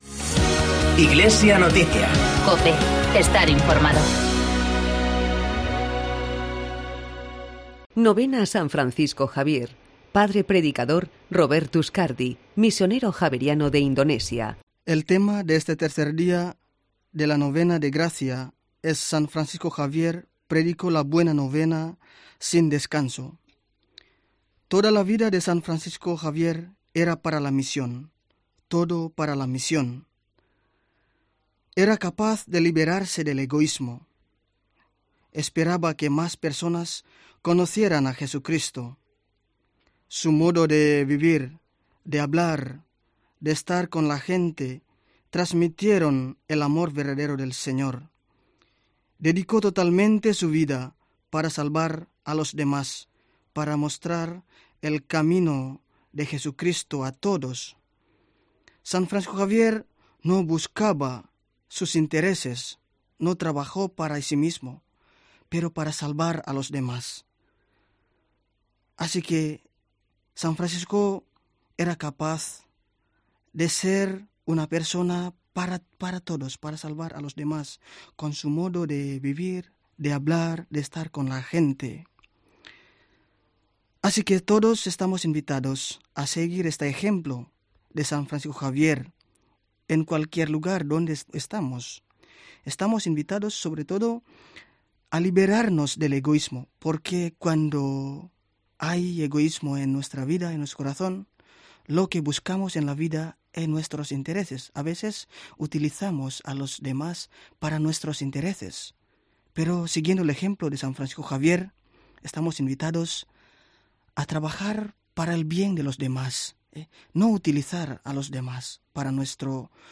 Padre Predicador